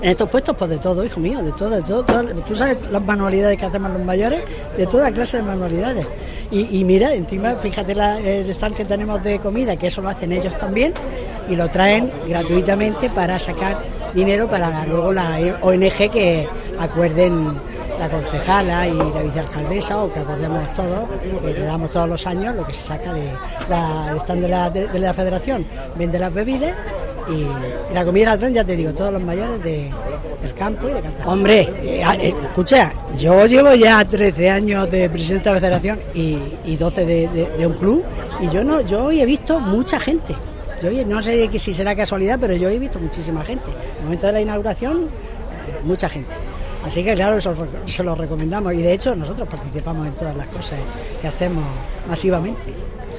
Audio: Declaraciones de la vicealcaldesa de Cartagena, Noelia Arroyo, durante su visita a la feria 'Femadis 19' (MP3 - 1,30 MB)